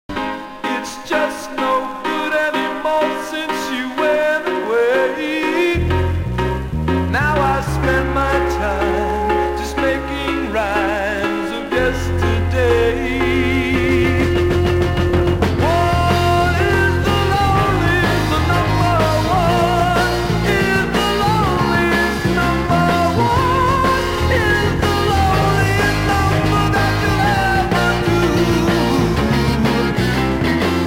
途中の盛り上がりはプログレッシヴと言っても過言ではないだろうか？